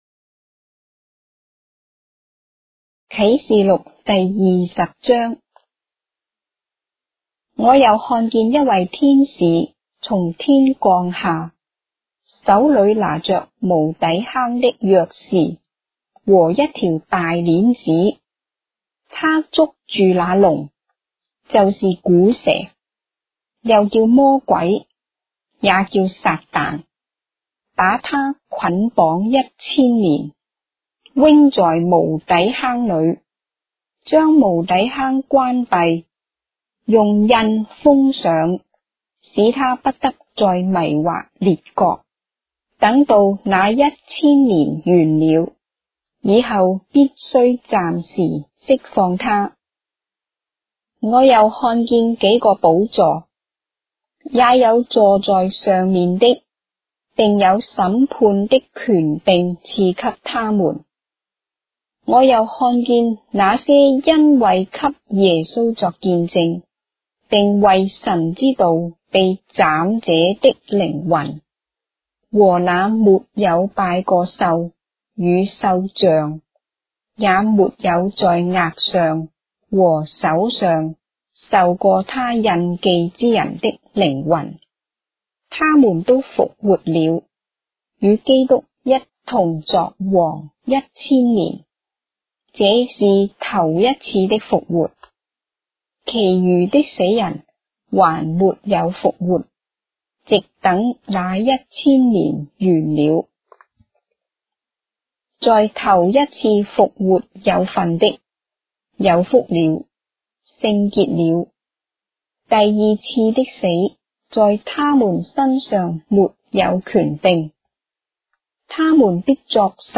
章的聖經在中國的語言，音頻旁白- Revelation, chapter 20 of the Holy Bible in Traditional Chinese